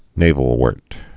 (nāvəl-wûrt, -wôrt)